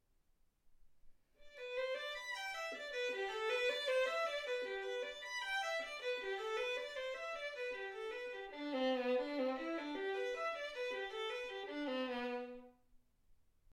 Hegedű etűdök Kategóriák Klasszikus zene Felvétel hossza 00:14 Felvétel dátuma 2025. december 8.